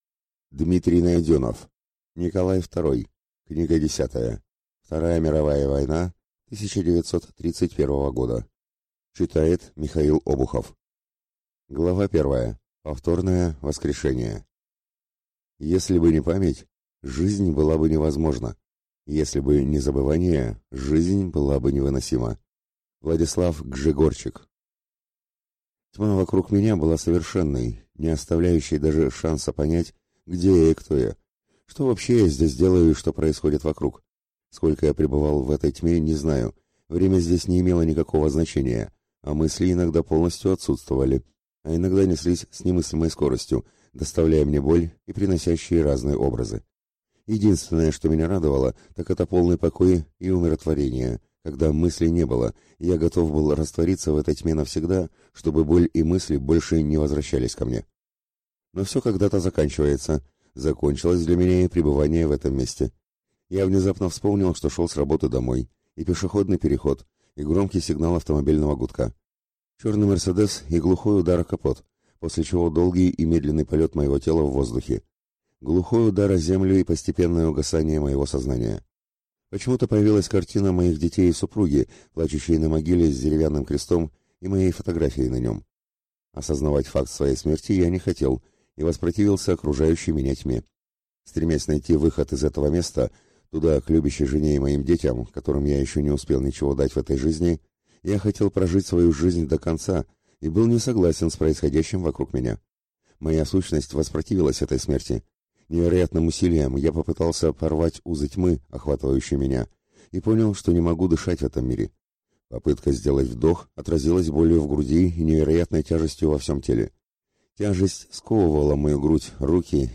Аудиокнига Николай Второй. Книга десятая. Вторая мировая война 1931 года | Библиотека аудиокниг